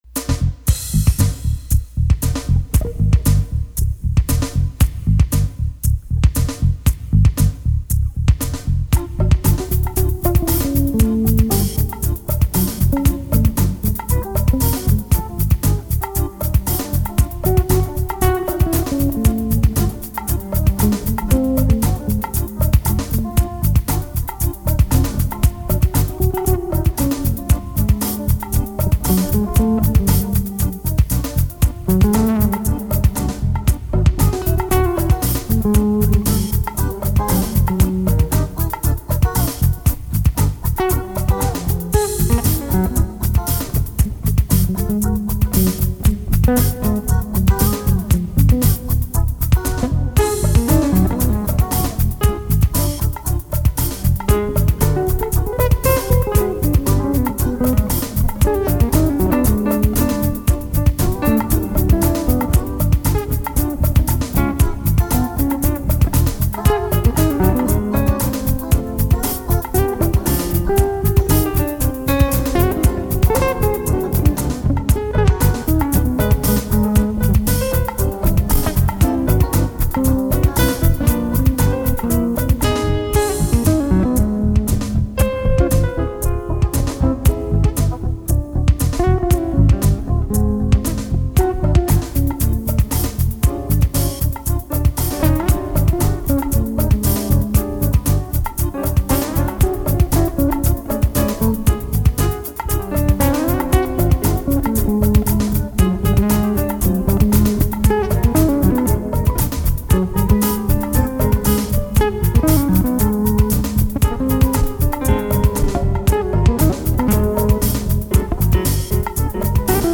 improvisational music
improvised monoloques, percussion
guitar
guitar, sax, keys, bass, drum prog,